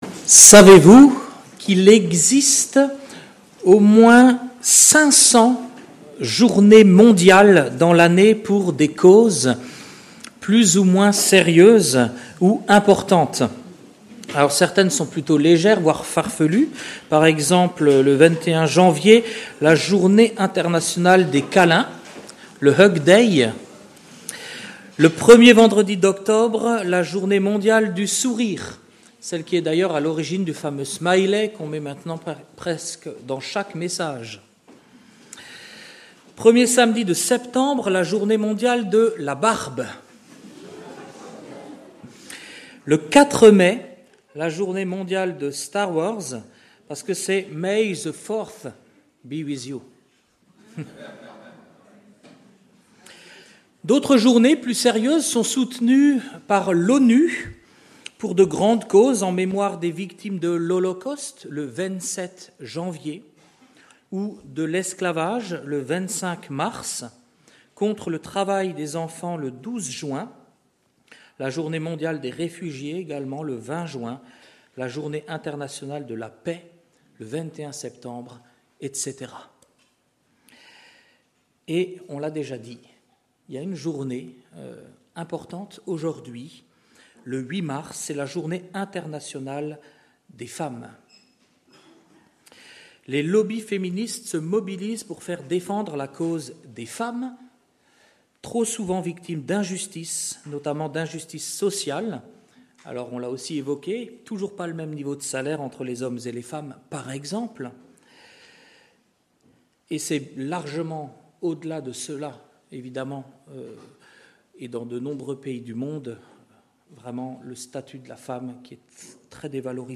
Culte du dimanche 8 mars 2026 – Église de La Bonne Nouvelle